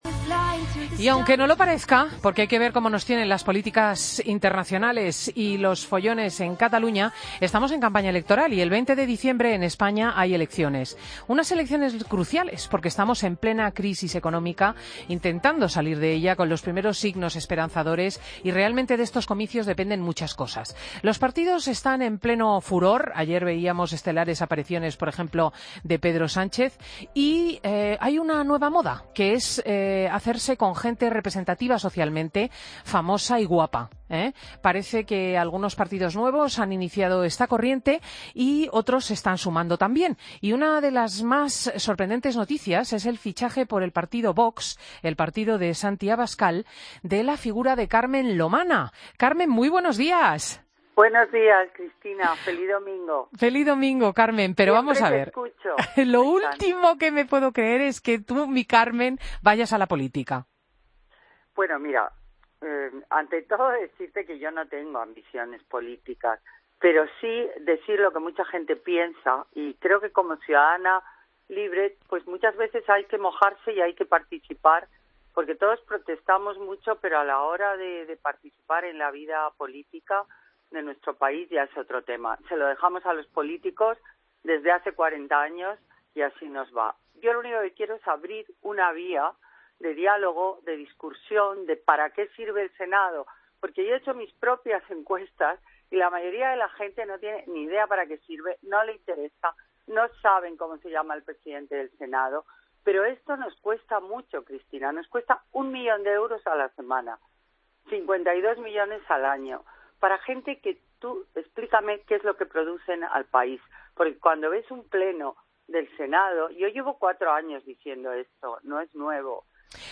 Entrevista a Carmen Lomana en Fin de Semana